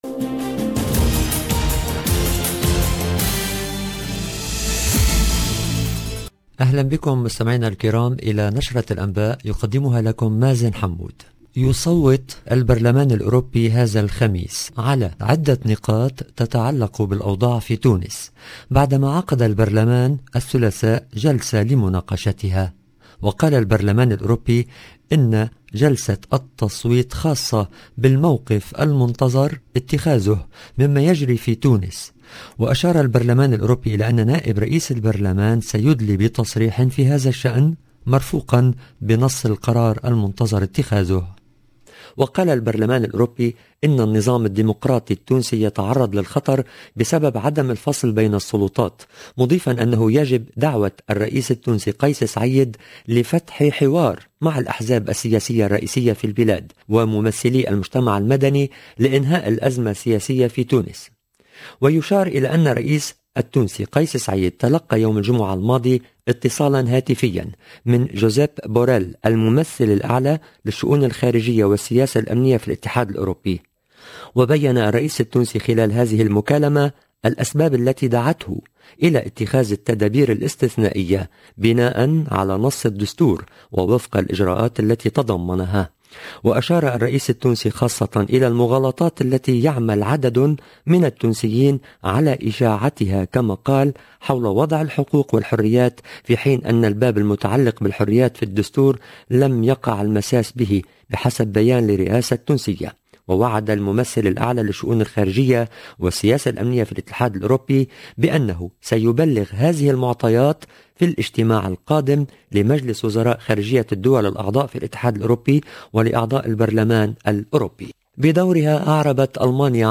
LE JOURNAL DU SOIR EN LANGUE ARABE DU 20/10/21